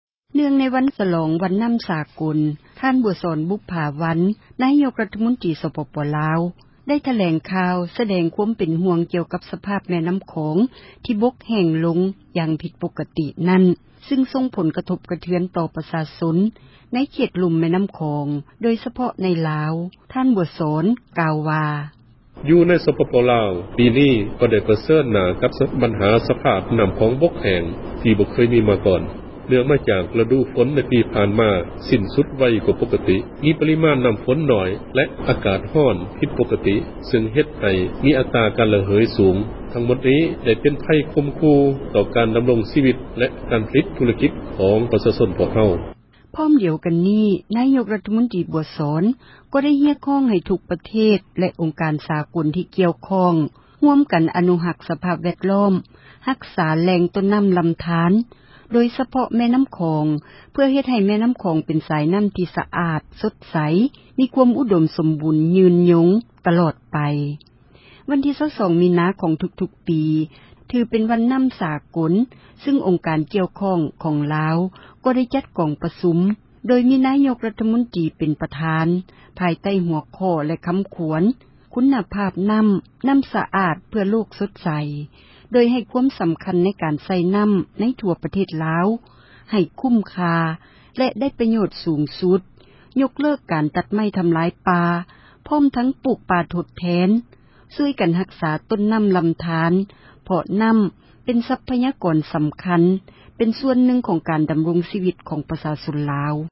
ເນື່ອງໃນ ວັນນໍ້າສາກົລ ທ່ານ ບົວສອນ ບຸບຜາວັນ ນາຍົກຣັຖມົນຕຣີ ສປປລາວ ໄດ້ຖແລງຂ່າວ ສະແດງຄວາມ ເປັນຫ່ວງ ກ່ຽວກັບ ສະພາບແມ່ນໍ້າຂອງ ທີ່ບົກແຫ້ງ ຢ່າງຜິດປົກຕິນັ້ນ ຊຶ່ງສົ່ງຜົລກະທົບ ຕໍ່ປະຊາຊົນ ໃນເຂດລຸ່ມ ແມ່ນໍ້າຂອງ ໂດຍສະເພາະໃນລາວ.